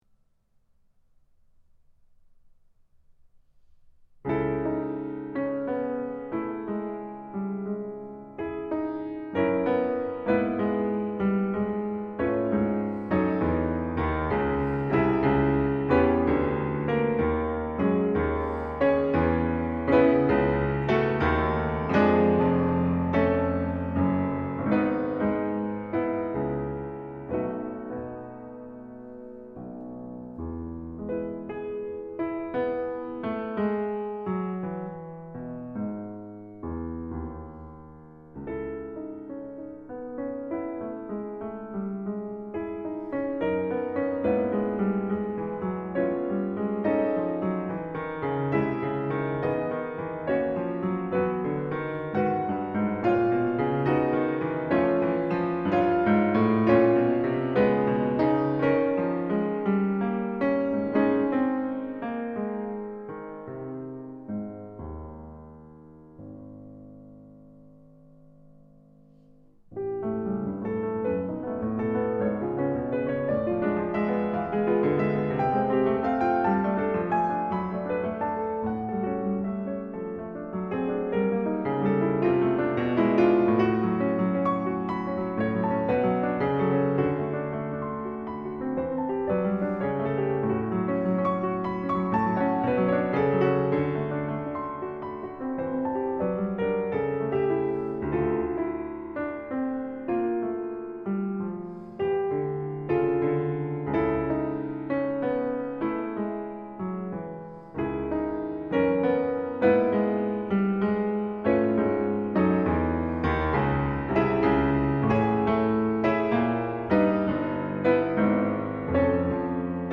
Japanese pianist